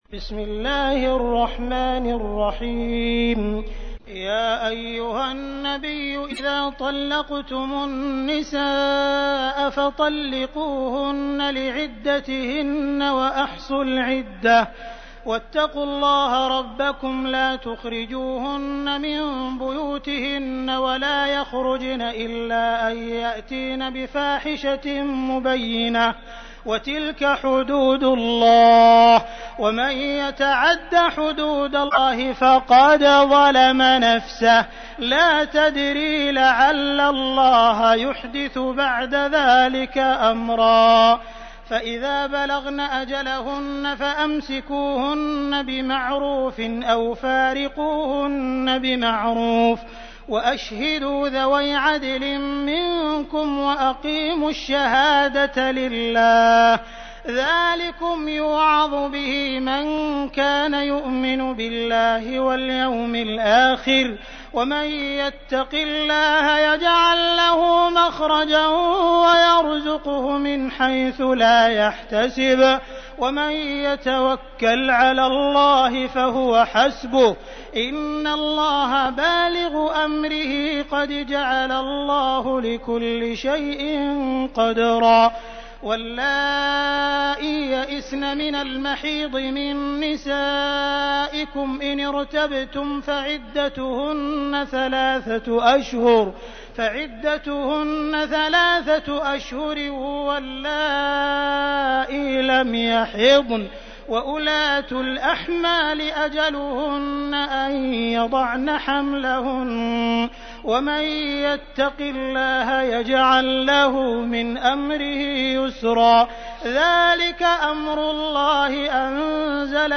تحميل : 65. سورة الطلاق / القارئ عبد الرحمن السديس / القرآن الكريم / موقع يا حسين